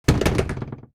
Door Close Sound
cartoon
Door Close